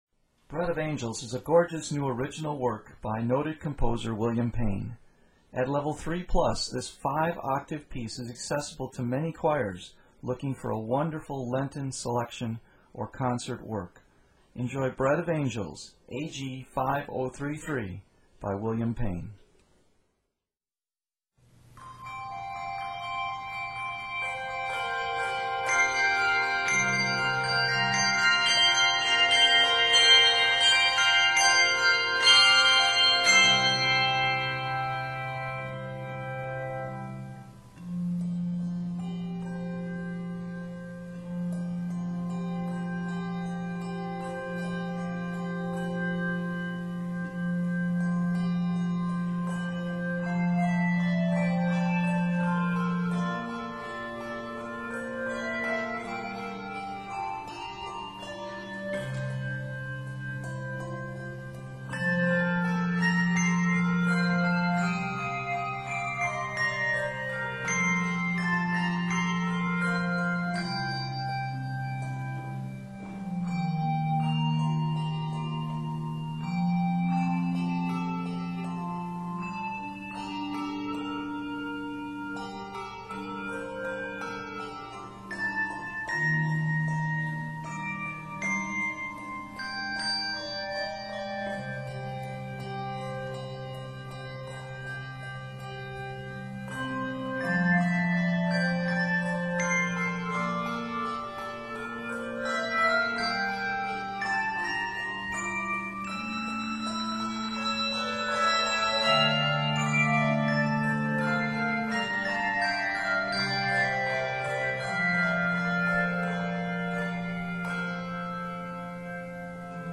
features low bass chimes throughout the piece